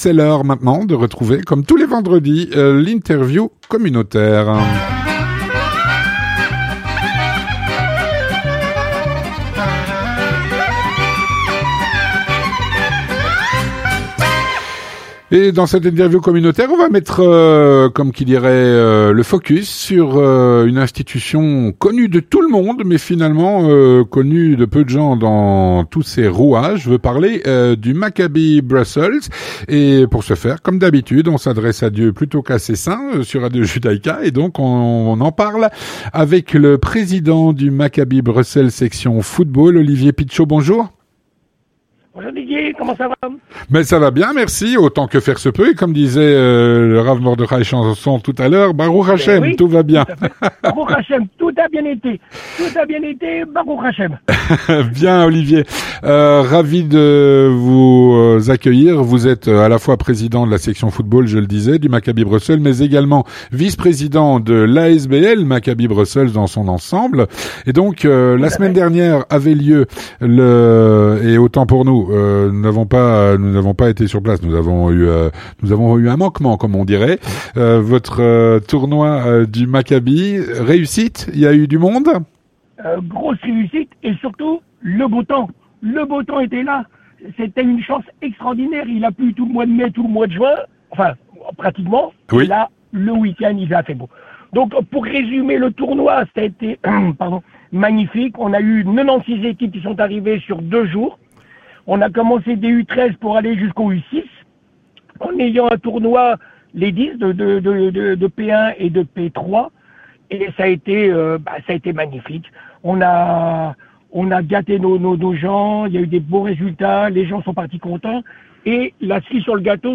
L'interview communautaire